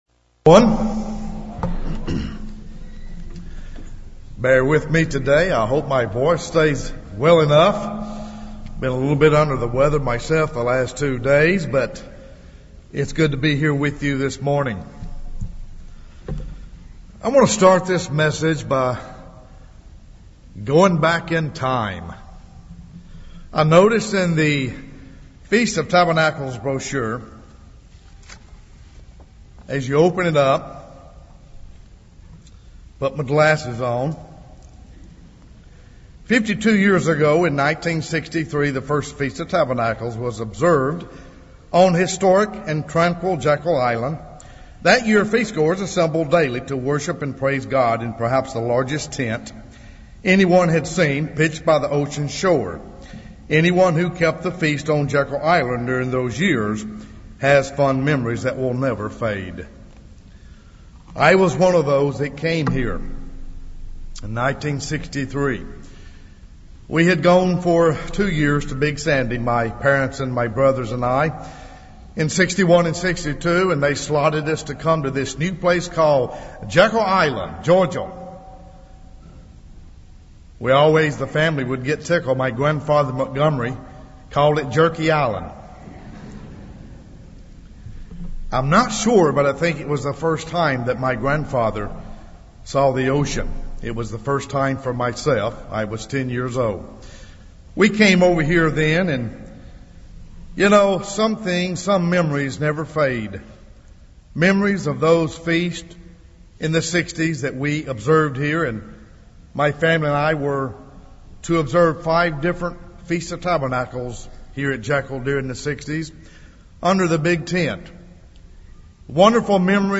Given in Tupelo, MS
UCG Sermon Studying the bible?